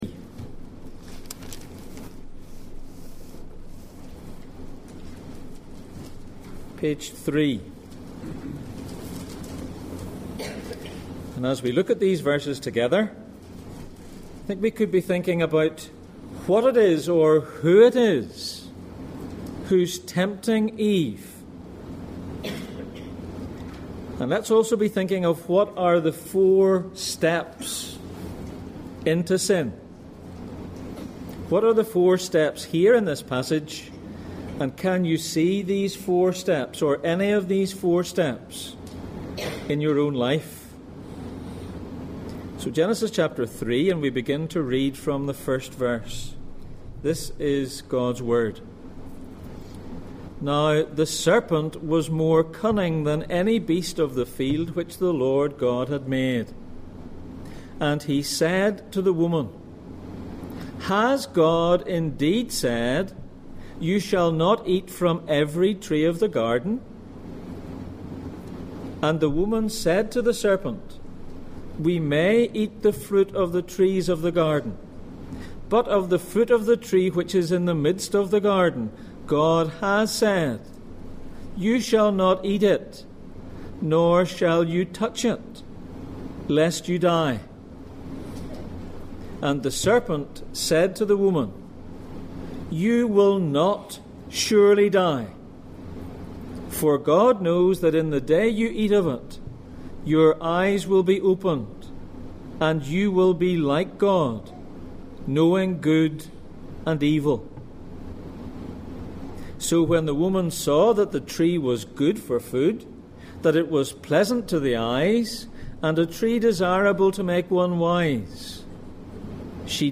Back to the beginning Passage: Genesis 3:1-6, Revelation 12:9 Service Type: Sunday Morning